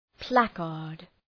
placard.mp3